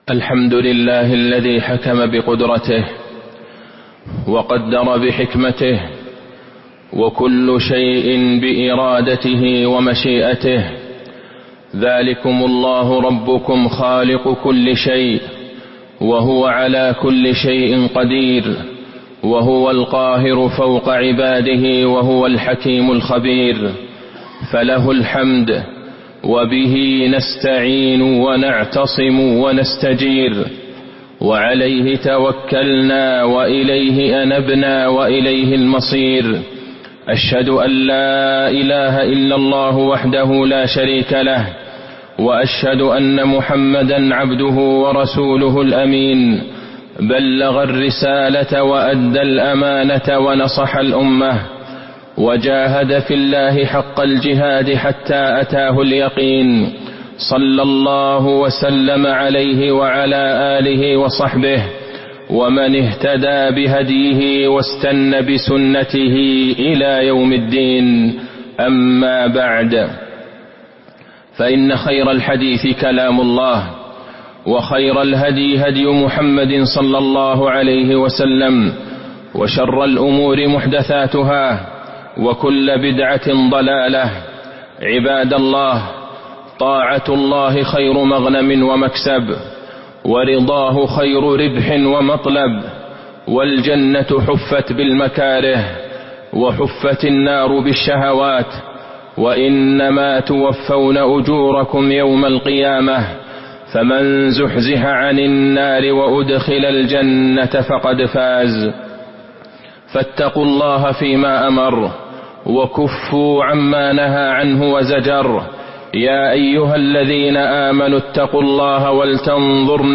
تاريخ النشر ٩ جمادى الآخرة ١٤٤٥ هـ المكان: المسجد النبوي الشيخ: فضيلة الشيخ د. عبدالله بن عبدالرحمن البعيجان فضيلة الشيخ د. عبدالله بن عبدالرحمن البعيجان الإيمان بالقضاء والقدر